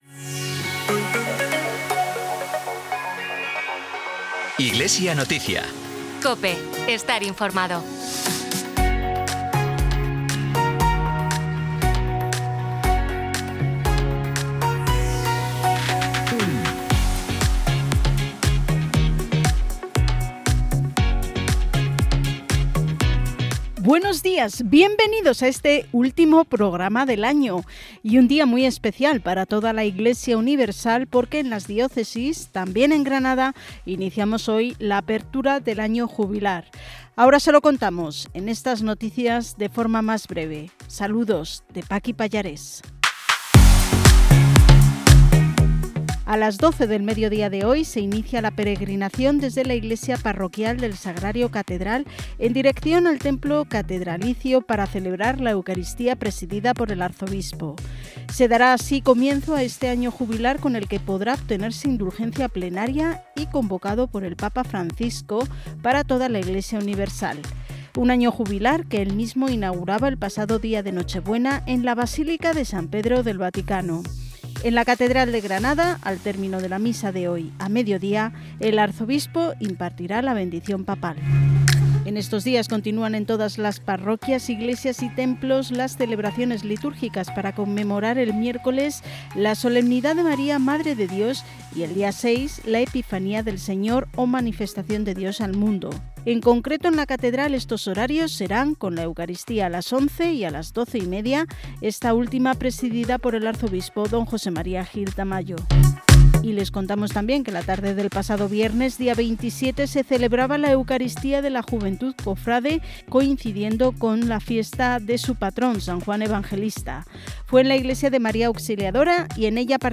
Informativo diocesano emitido en COPE Granada y COPE Motril.